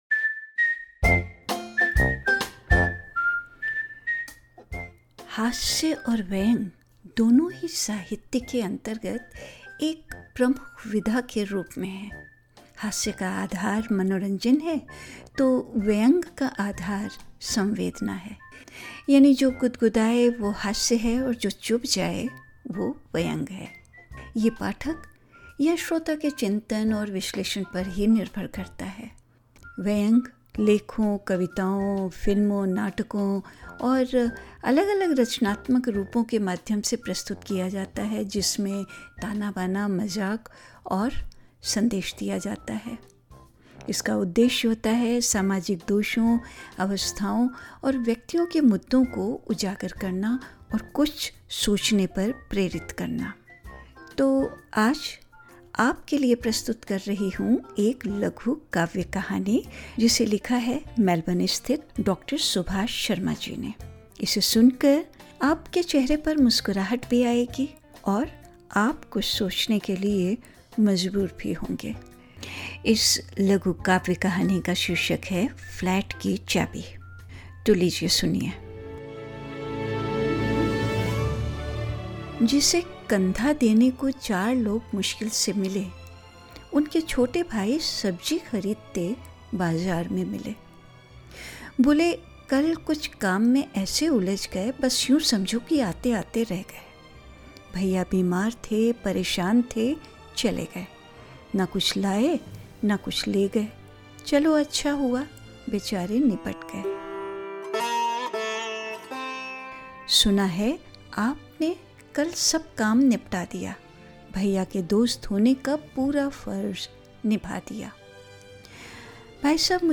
हास्य और व्यंग: एक लघु काव्य कथा -'फ्लैट की चाबी'
hindi-flat-ki-chabi-short-poetry-web.mp3